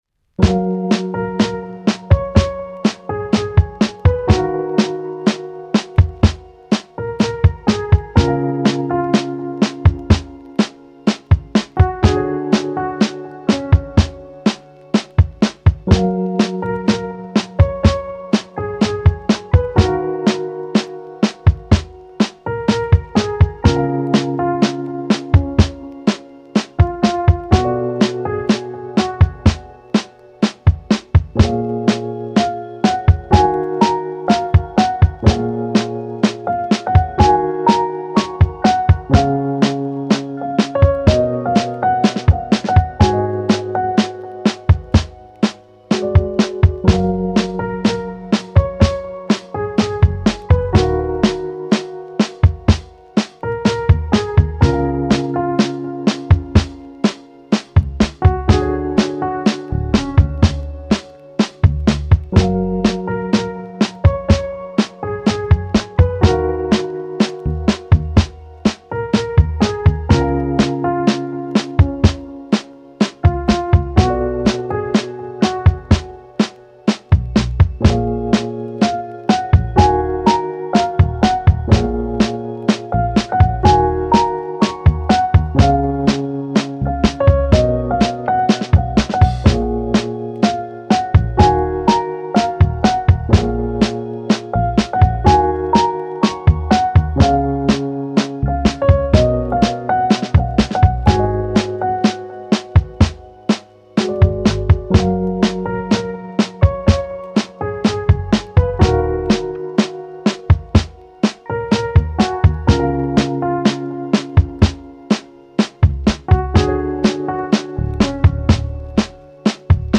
チル・穏やか フリーBGM